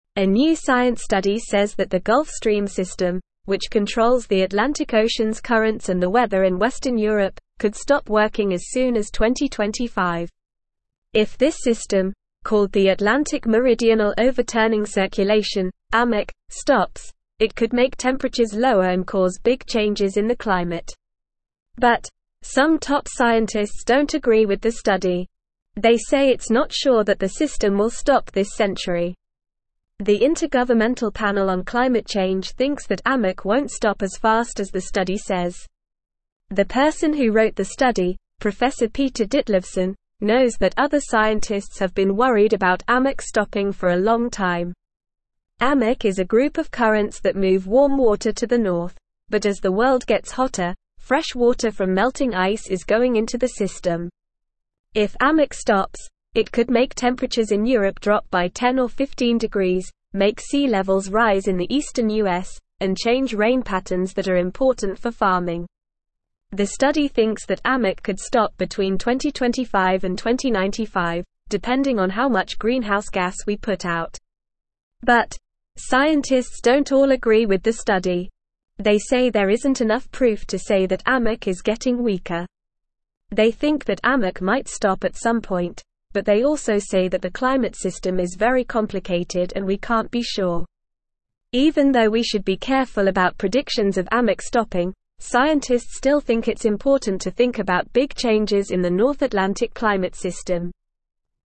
Normal
English-Newsroom-Lower-Intermediate-NORMAL-Reading-Gulf-Stream-System-May-Stop-Changing-Climate.mp3